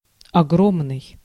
Ääntäminen
France: IPA: [ʒi.gɑ̃.tɛsk]